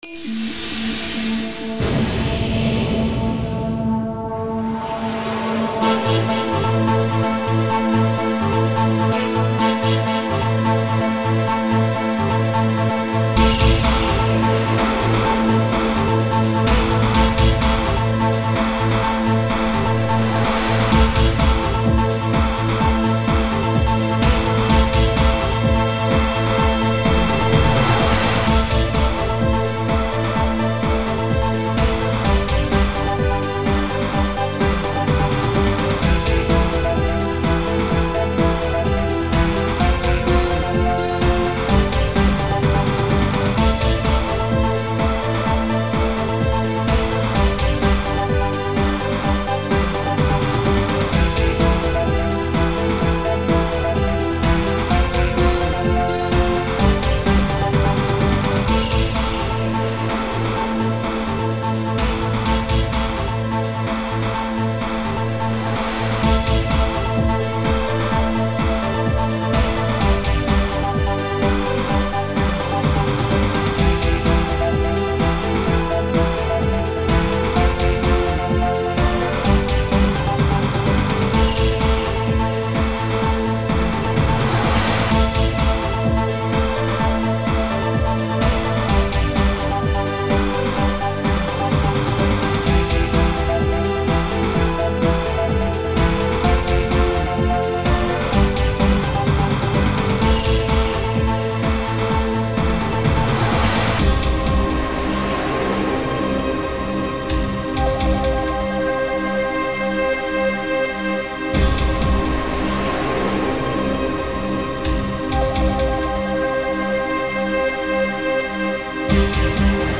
Synthpop · 03:45:00